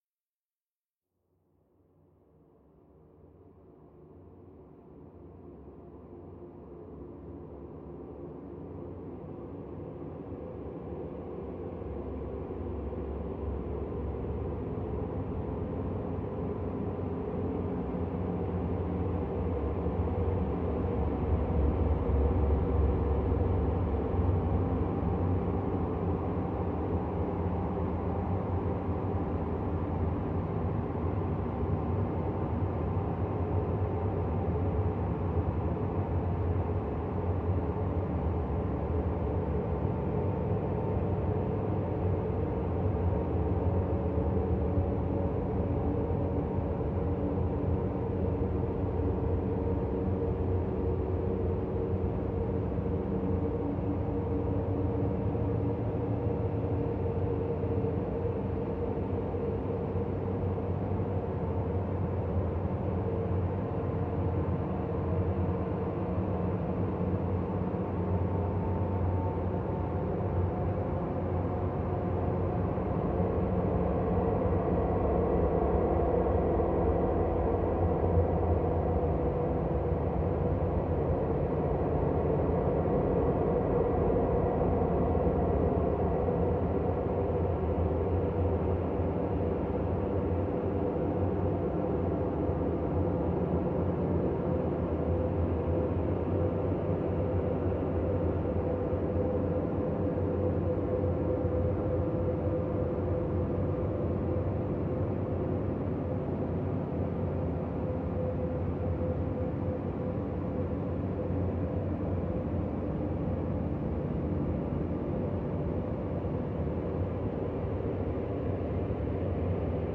Reimagined sound of Löbbeckes Insel park, Braunschweig, Germany.